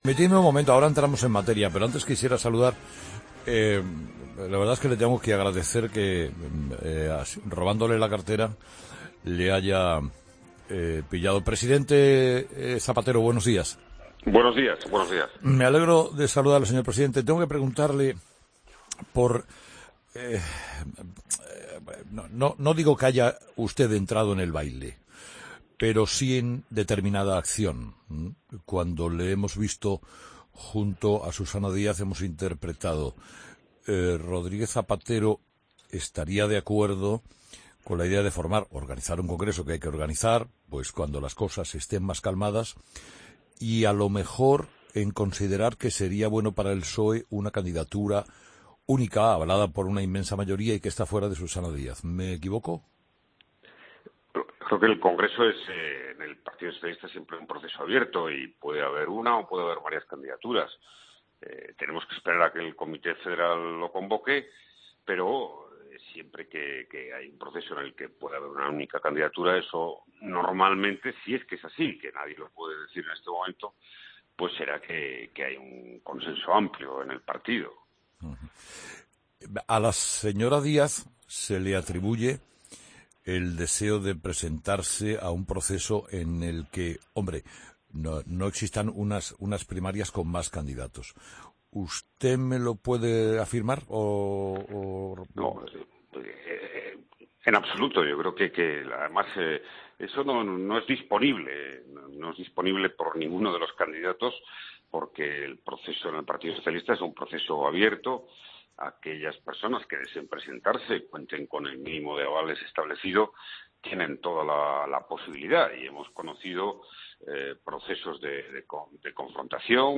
Escucha la entrevista completa a José Luis Rodríguez Zapatero en 'Herrera en COPE'